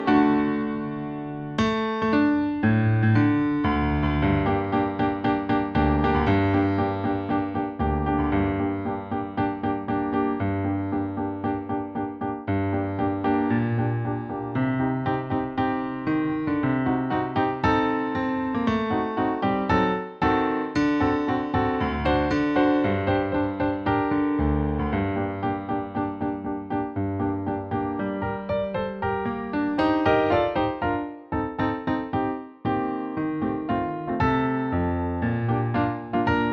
Produkt zawiera nagranie akompaniamentu pianina
I część: 116 bmp – wersja wirtuozowska
Nagranie dokonane na pianinie cyfrowym, strój 440Hz